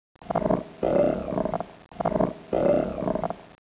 zombi_bomb_idle_10.wav